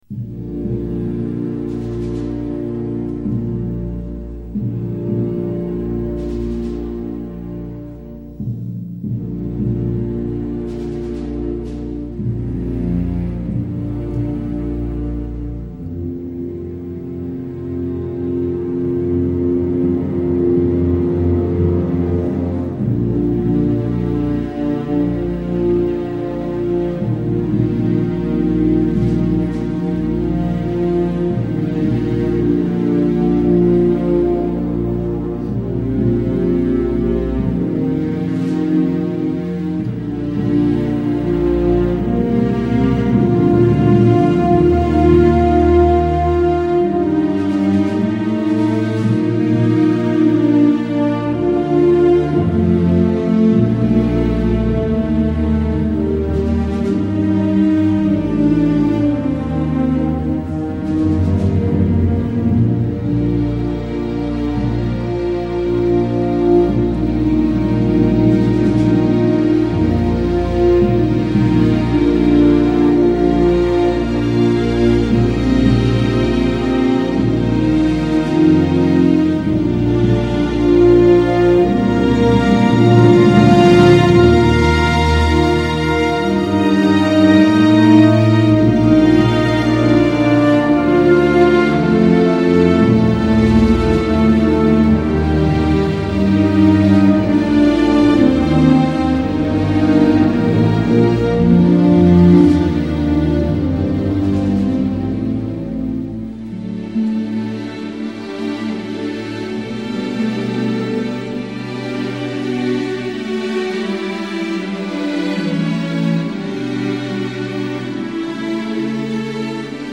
hymn-to-the-fallen1.mp3